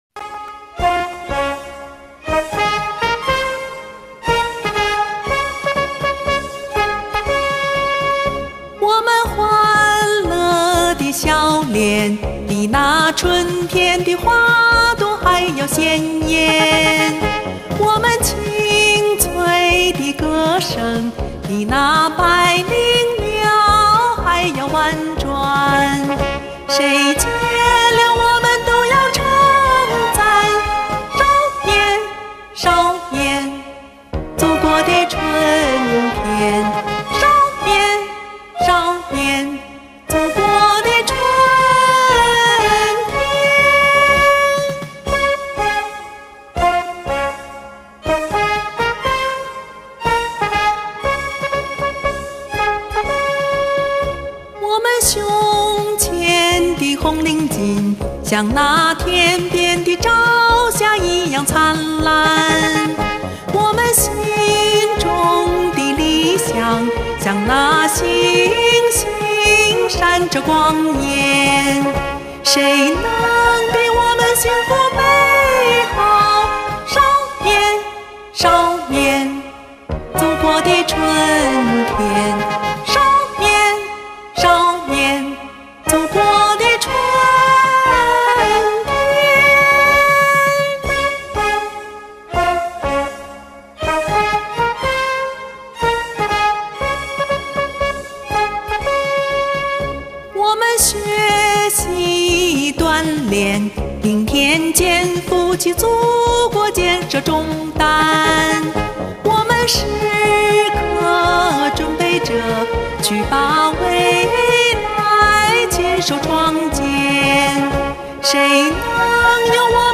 你这嗓子怎么越来越嫩呢，这也太能了，唱啥是啥，活脱脱